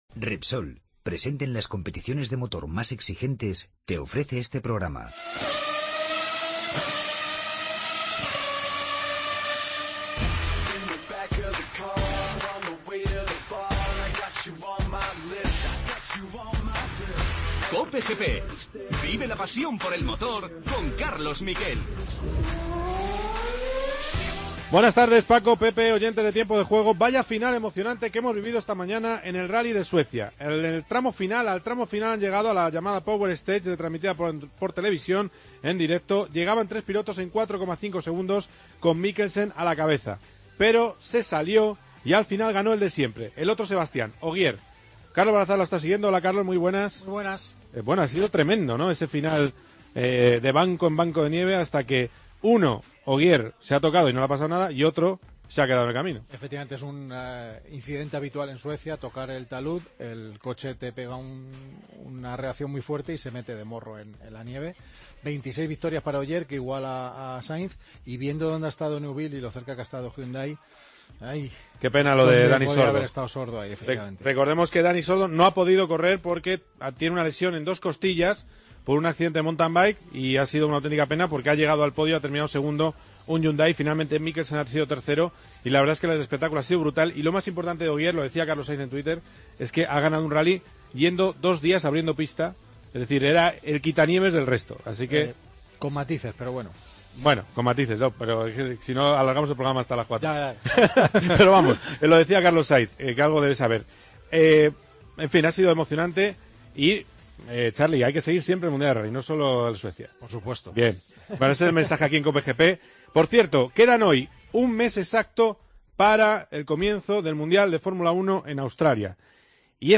Entrevista al nuevo piloto de Nissan para Le Mans, Lucas Ordóñez. Entrevista a la estrella de motos más precoz de la historia, Fabio Quartararo.